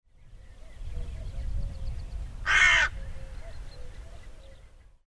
Hooded Crow  Corvus cornix
Sonogram of Hooded Crow call
Tooma, Endla Looduskaitseala, Estonia  58º 52' 35.3'' N  26º 16' 22.7'' E  22 May 2010, 00:01h
Call from damp scrub (River Warbler and Corncrake in the background).